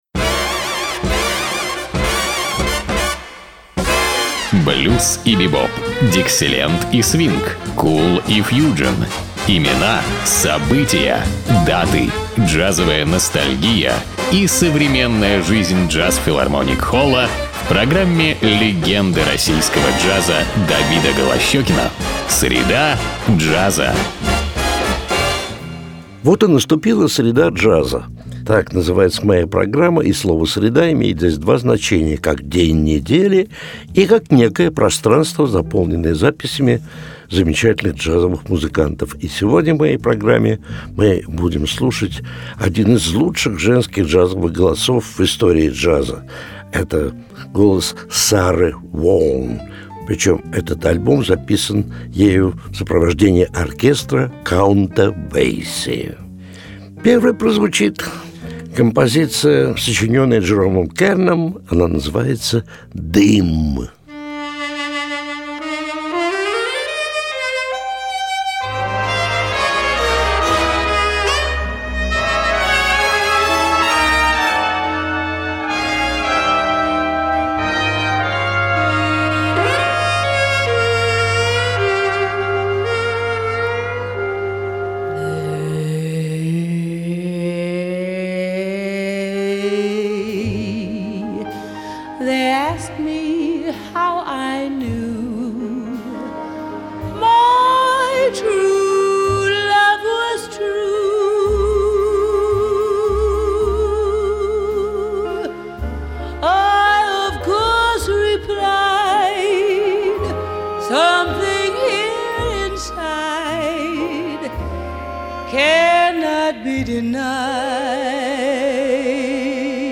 американской джазовой вокалистки